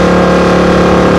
charger2012_mid.wav